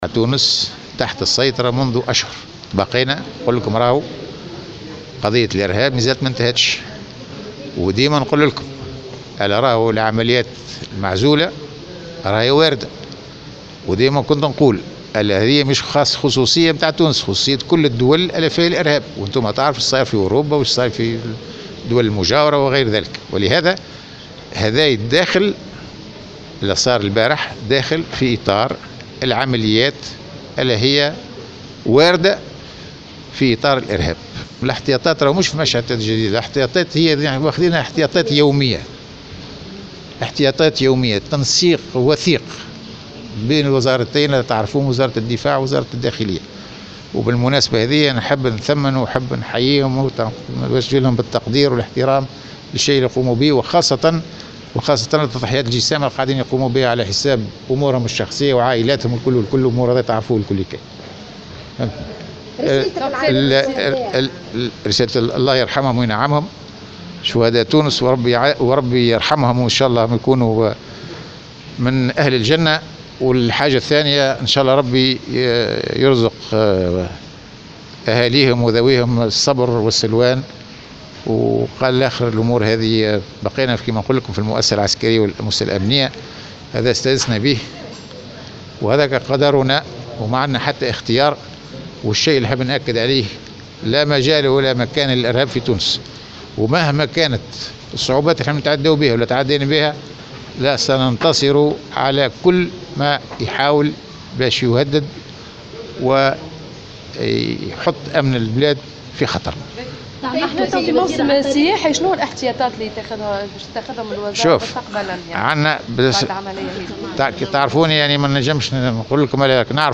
قال وزير الدفاع الوطني عبد الكريم الزبيدي، في تصريحات لـ "الجوهرة اف أم" اليوم الاثنين، إن الارهاب لا يقتصر على تونس وليس "خصوصية تونسية"، بحسب تعبيره في تعليقه على عملية جندوبة الارهابية أمس.